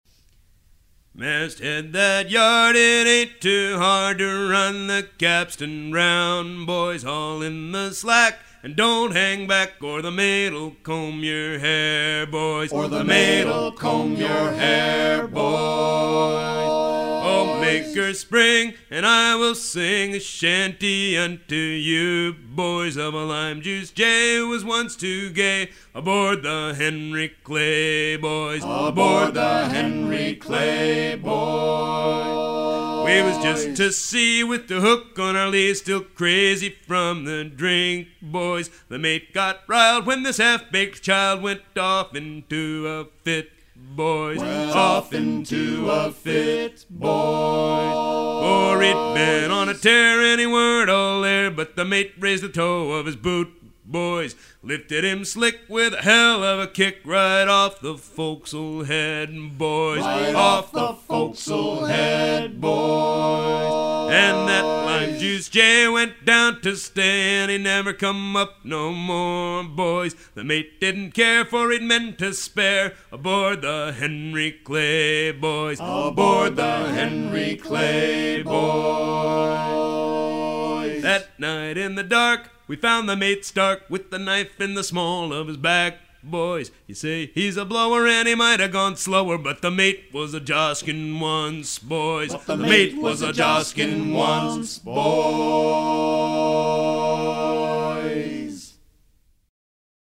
circonstance : maritimes
Pièce musicale éditée